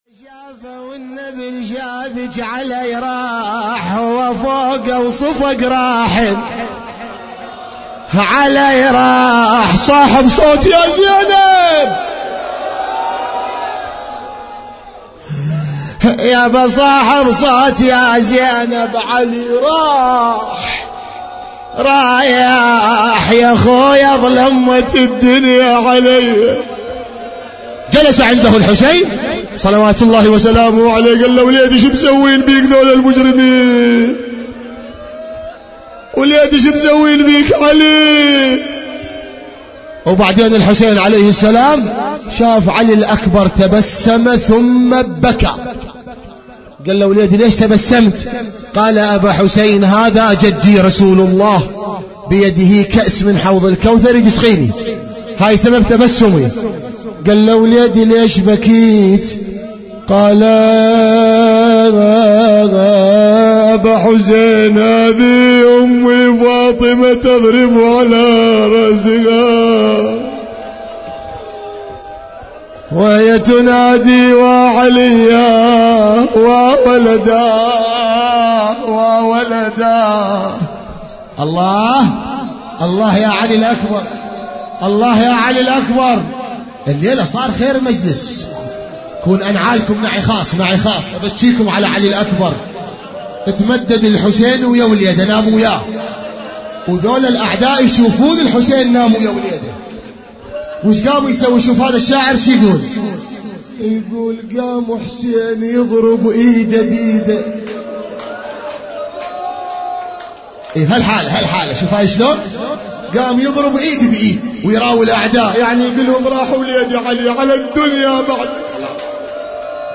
ليلة تاسع من محرم